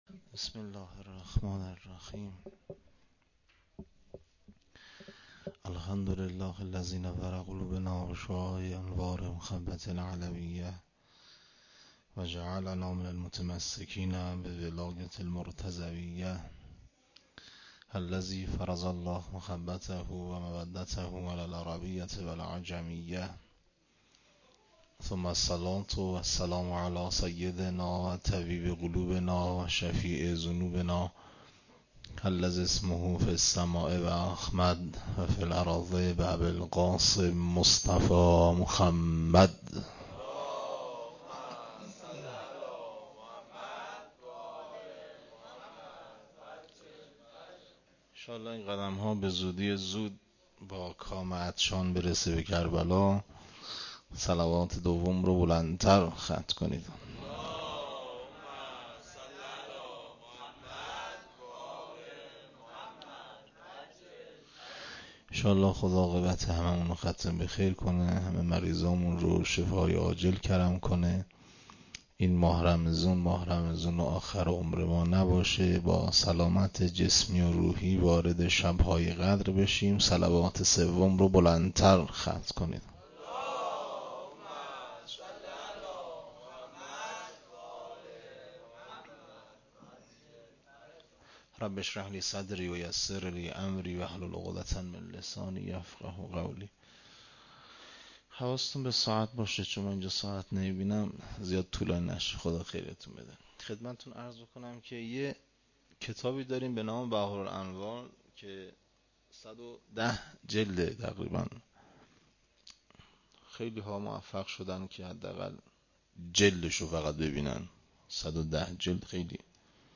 ولادت امام حسن-سخنراني
01-MILAD-EMAM-HASAN-93-sokhanrani.mp3